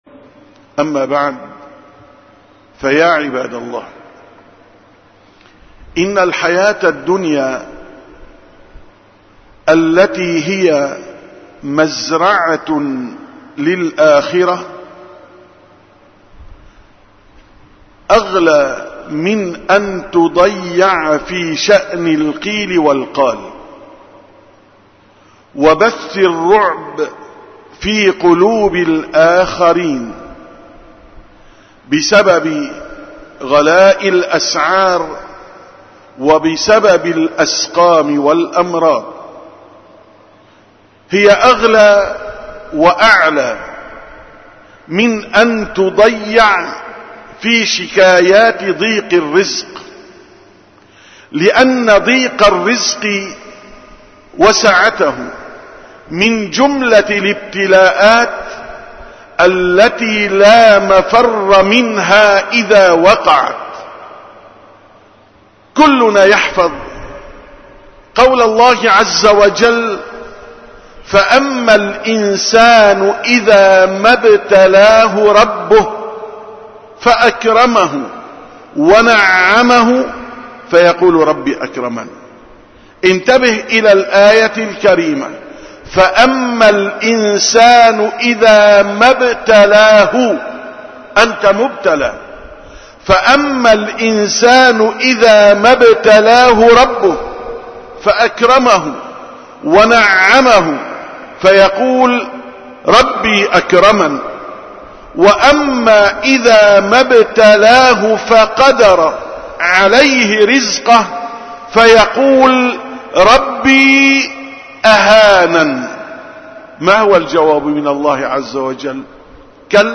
713ـ خطبة الجمعة: لا تقل قَلَّ رزقي